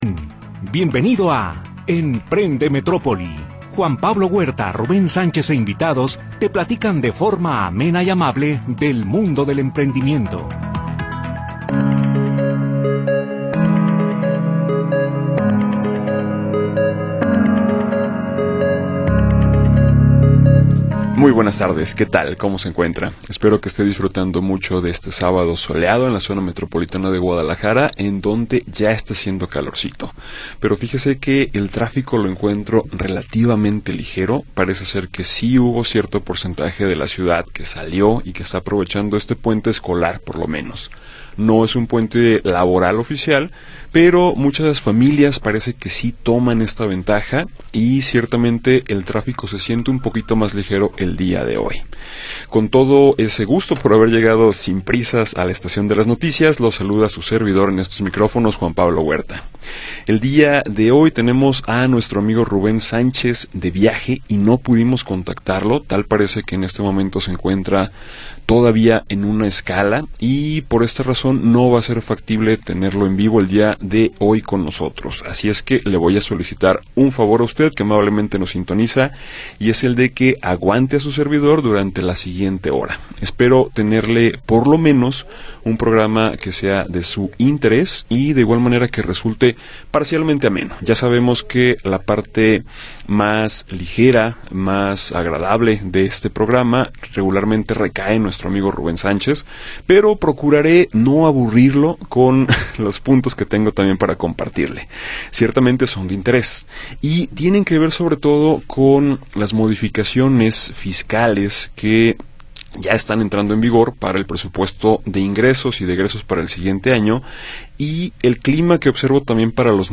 te platican de forma amable y amena acerca del mundo del emprendimiento.